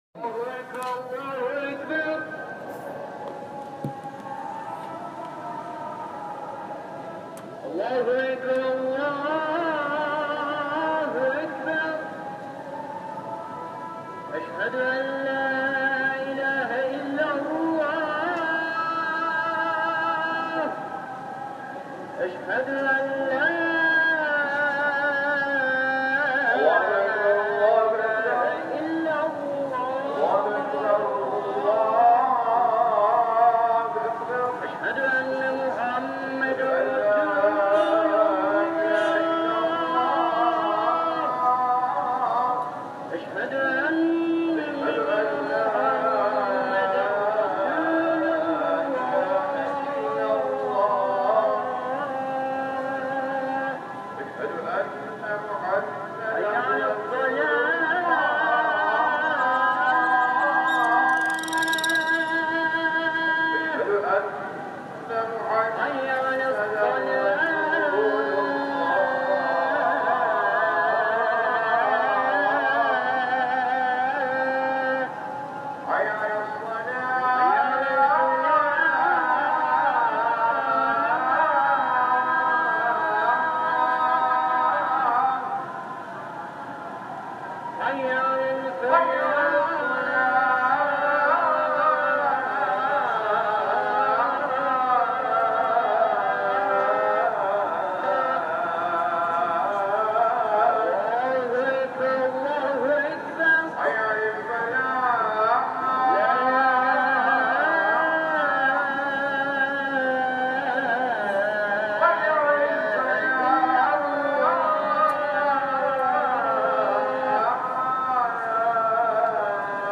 Call for Prayers
The second is an evening call (much shorter) recorded from our hotel window.   There seems to be coordination between the 3 mosques as only 1 of the 3 will be broadcasting at a time during the 2-6 minute calls.
Evening-Call-For-Prayers.m4a